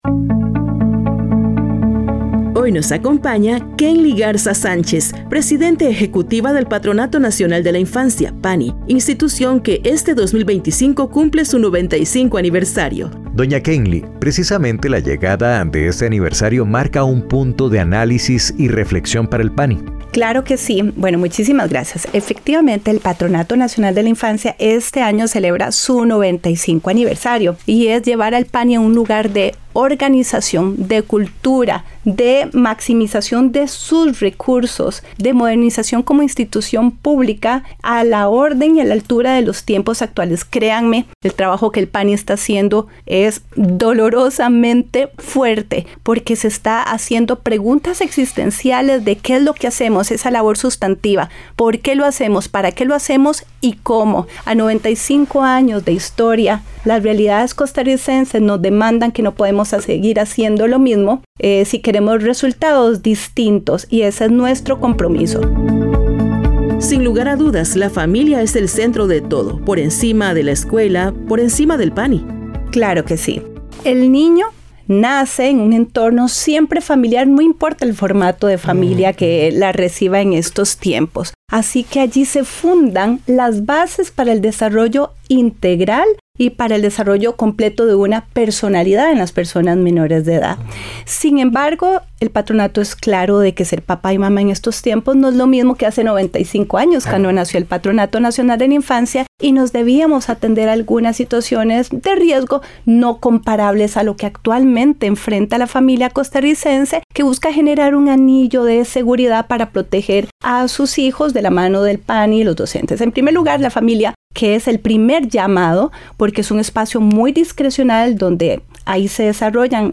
Entrevista a Kennly Garza, presidente ejecutiva del PANI